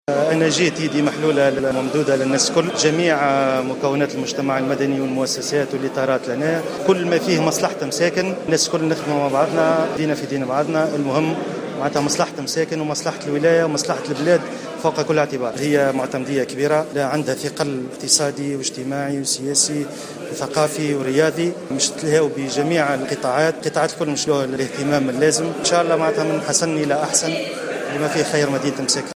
وأكد العربي بن الصادق قرطاس في تصريح ل"الجوهرة أف أم" اليوم على ضرورة العمل المشترك والاهتمام بمختلف القطاعات الاجتماعية والثقافية والتربوية للنهوض بالجهة.